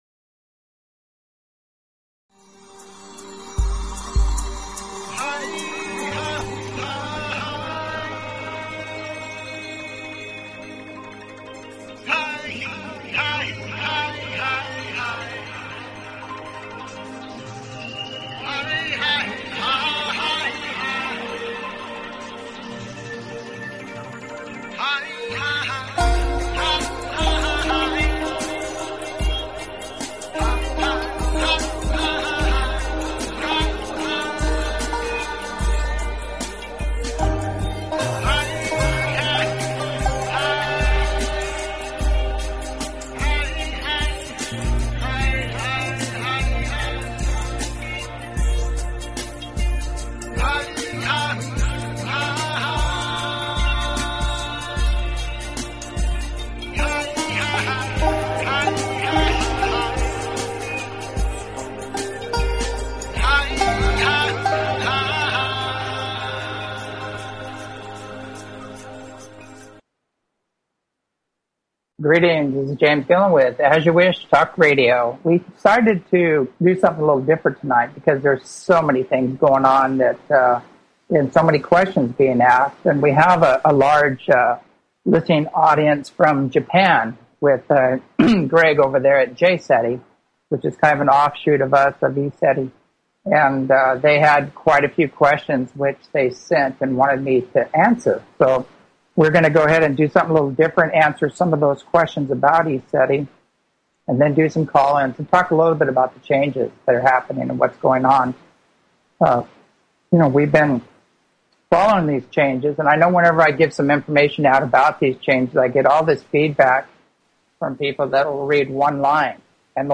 Talk Show Episode, Audio Podcast, As_You_Wish_Talk_Radio and Courtesy of BBS Radio on , show guests , about , categorized as
JCETI Japan, answering questions from overseas listeners, call ins